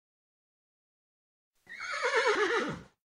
دانلود صدای شیهه اسب 3 از ساعد نیوز با لینک مستقیم و کیفیت بالا
جلوه های صوتی